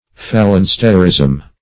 Search Result for " phalansterism" : The Collaborative International Dictionary of English v.0.48: Phalansterism \Pha*lan"ster*ism\, Phalansterianism \Phal`an*ste"ri*an*ism\, n. A system of phalansteries proposed by Fourier; Fourierism.